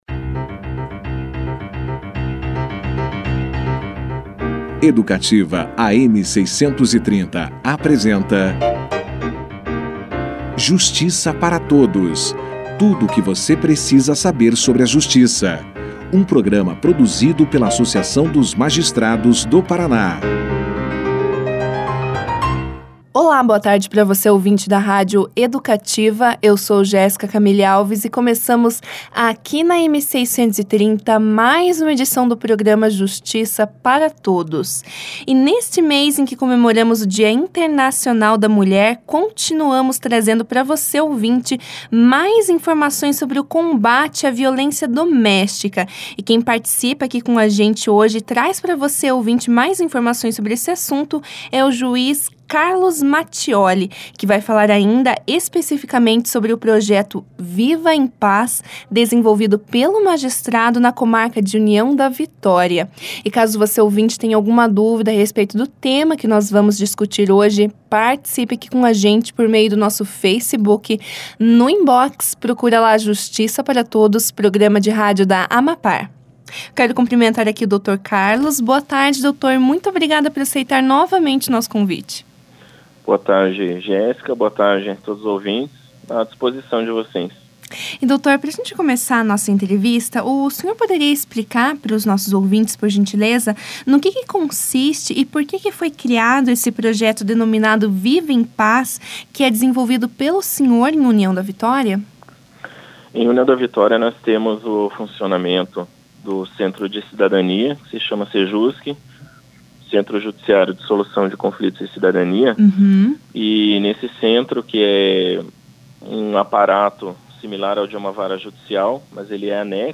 O programa Justiça para Todos teve a participação nesta quinta-feira (23) do juiz Carlos Eduardo Mattioli. O magistrado explicou ao ouvinte da rádio Educativa, AM 630, sobre o projeto desenvolvido por ele na comarca de União da Vitória, intitulado “Viva em Paz”, que combate a violência contra a mulher, por meio de um trabalho com os agressores denunciados.
Já no início da entrevista, o magistrado explicou o que é o projeto e como ele funciona na prática.